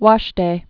(wŏshdā, wôsh-)